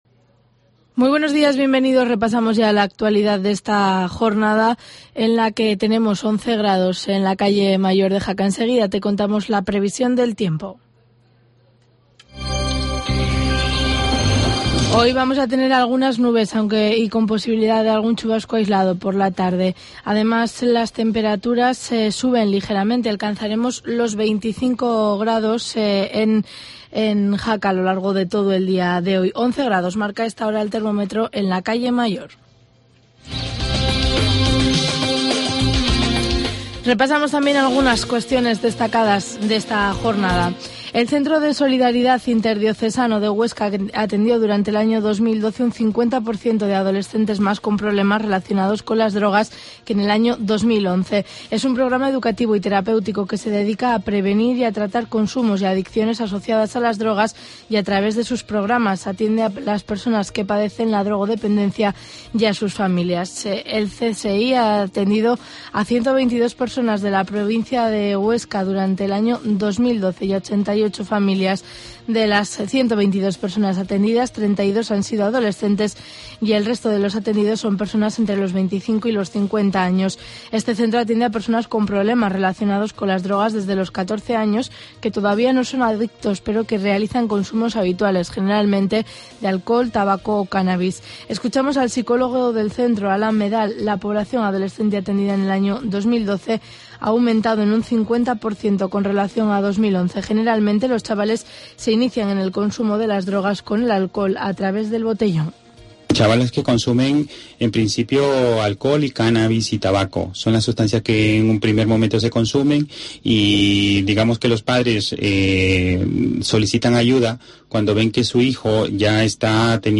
Informativo matinal, jueves 27 de junio, 7.25 horas